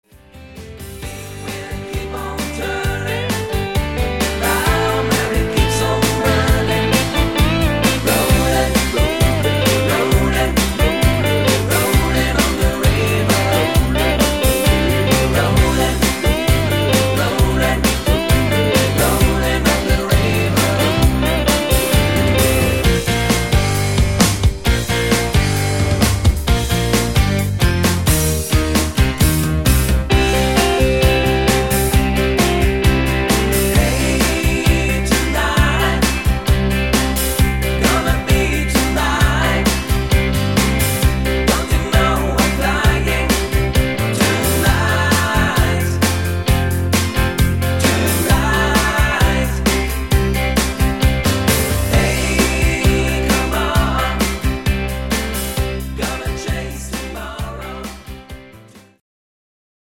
ETS (easy to sing) = einfacher und
leichter zu singende Tonarten